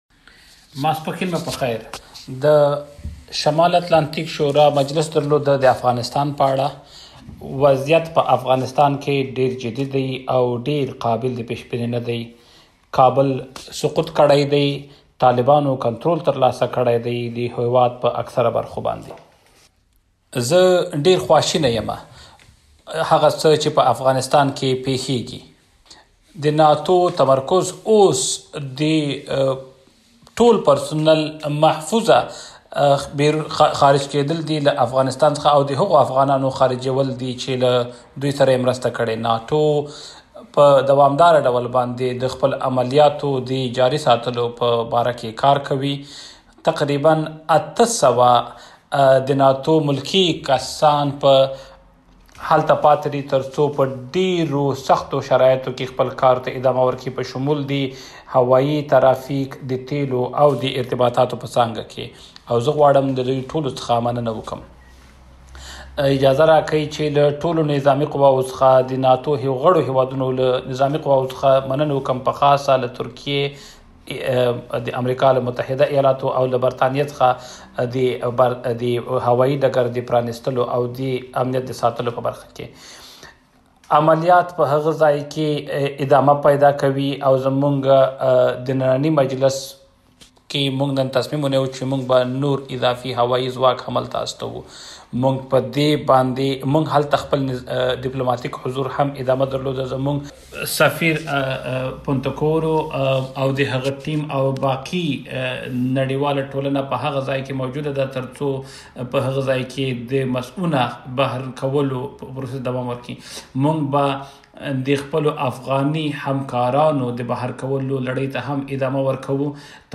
Press briefing on Afghanistan
by NATO Secretary General Jens Stoltenberg